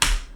soft-hitwhistle.wav